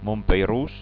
Prononcer "Mounpeÿrous".